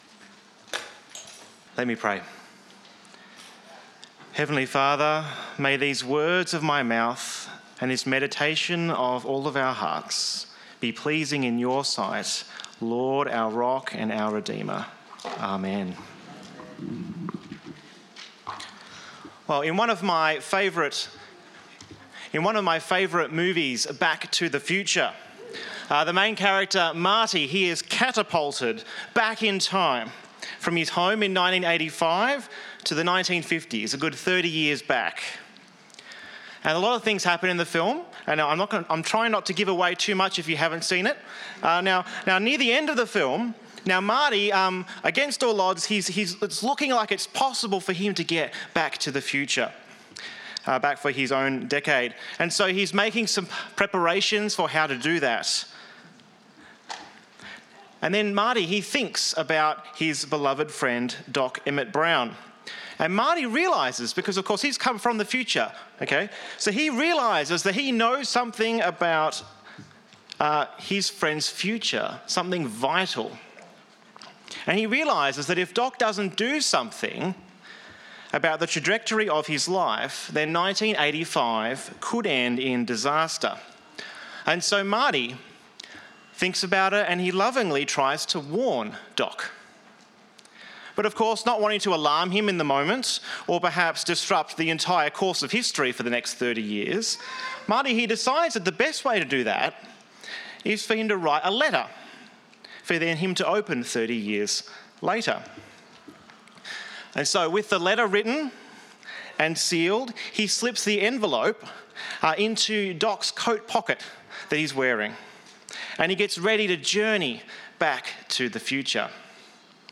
The Bible reading is Proverbs 4:1-27.